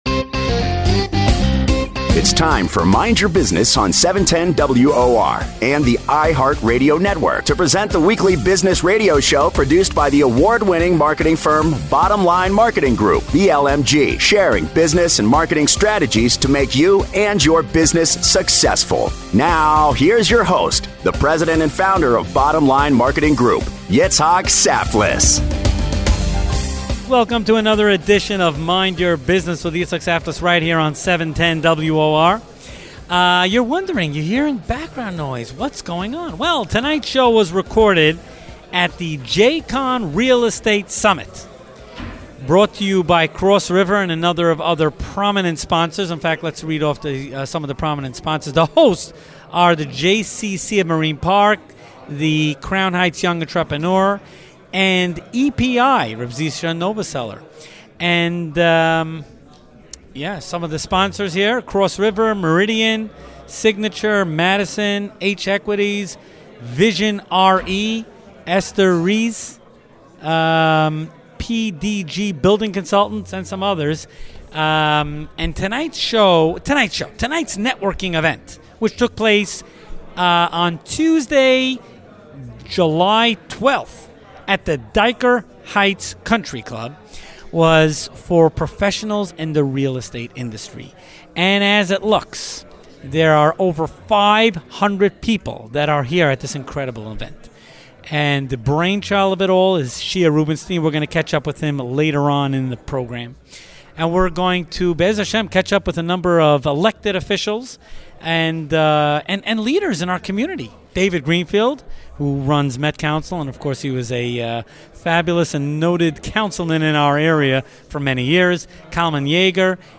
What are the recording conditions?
Recorded live from the recent JCon Conference